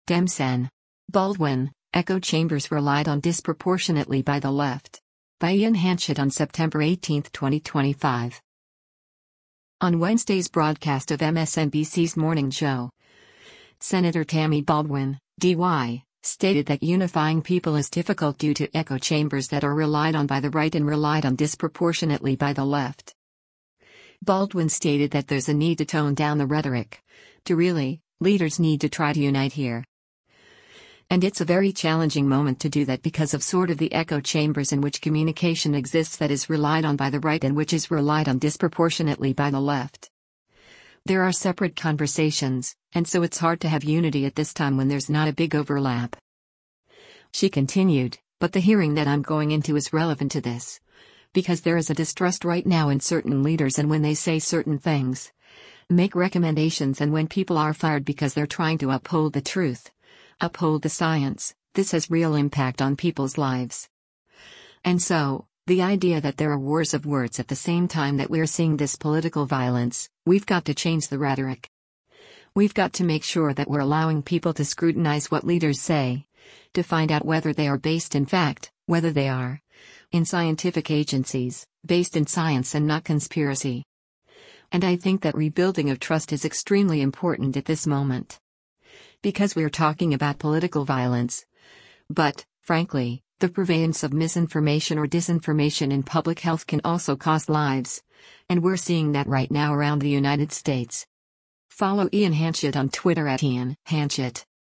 On Wednesday’s broadcast of MSNBC’s “Morning Joe,” Sen. Tammy Baldwin (D-WI) stated that unifying people is difficult due to echo chambers that are relied on by the right and “relied on disproportionately by the left.”